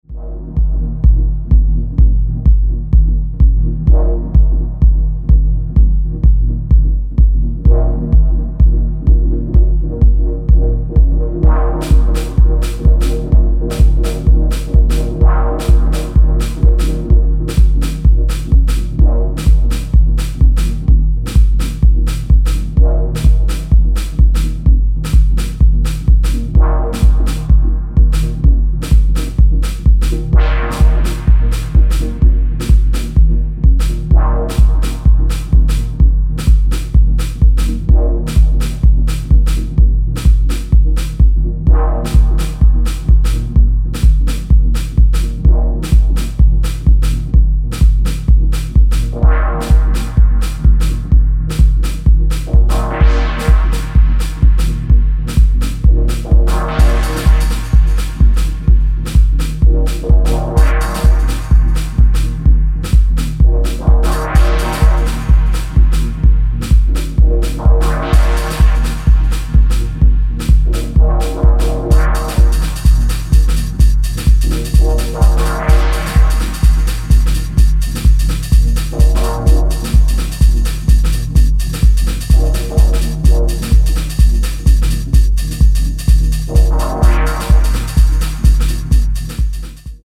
ジャジーなブラシスネアが良いアクセントになったドープなダブ・ミニマル